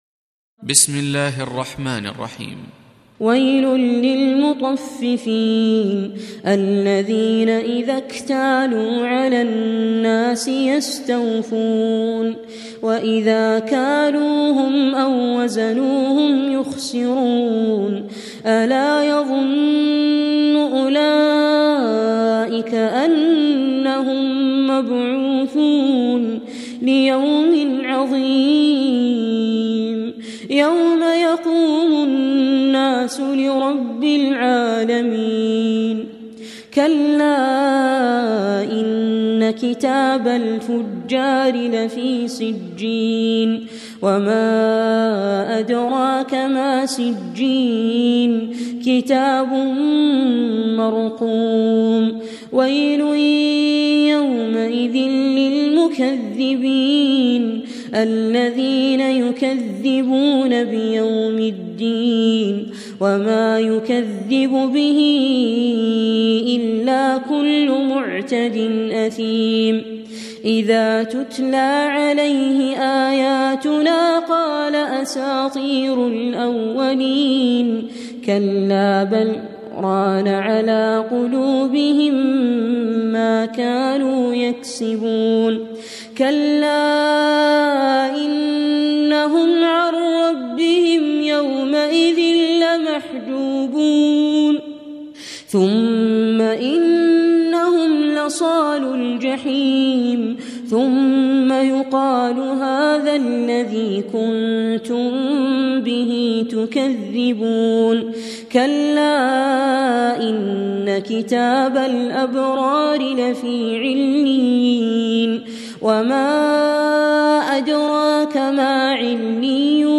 Reciting Murattalah Audio for 83. Surah Al-Mutaffifîn سورة المطفّفين N.B *Surah Includes Al-Basmalah